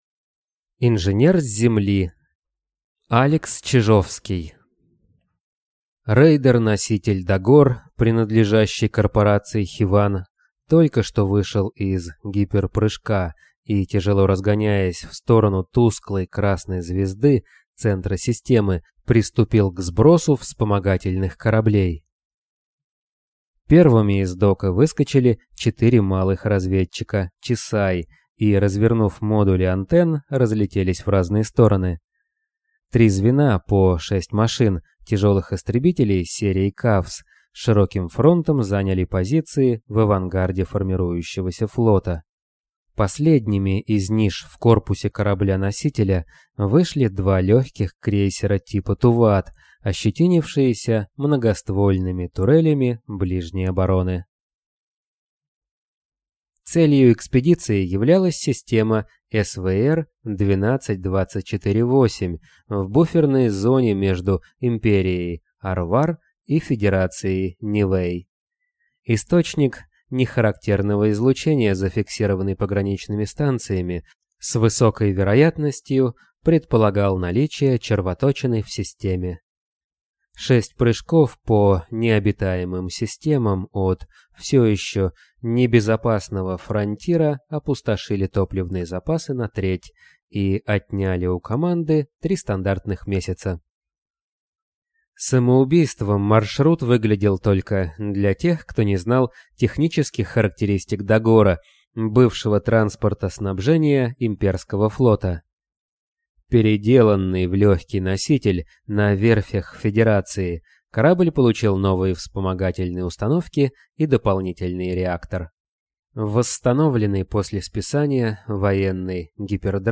Аудиокнига Инженер с Земли | Библиотека аудиокниг
Прослушать и бесплатно скачать фрагмент аудиокниги